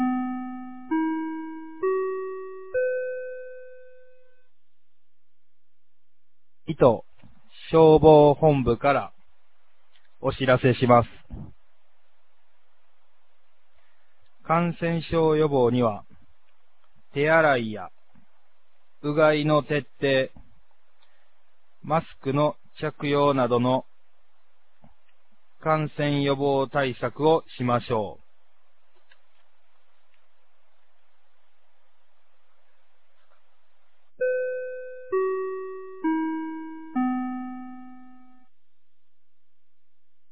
2024年12月02日 10時00分に、九度山町より全地区へ放送がありました。
放送音声